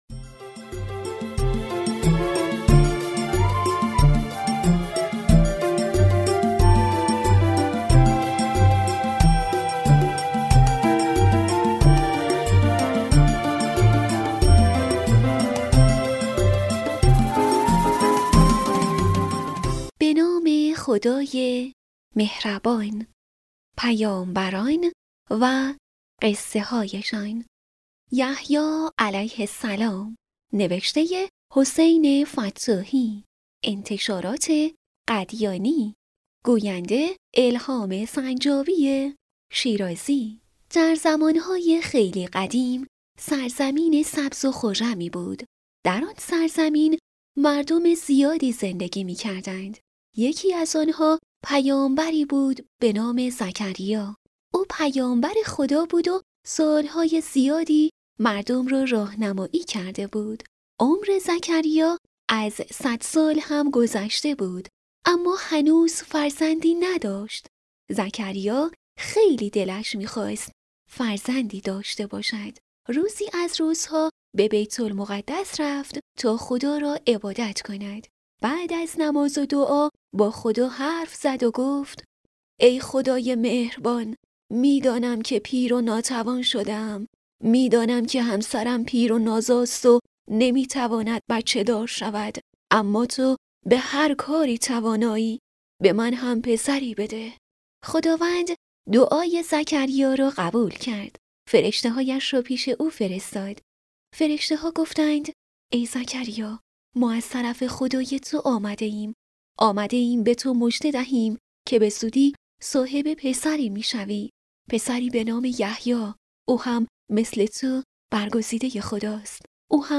کتاب صوتی «پیامبران و قصه‌هایشان»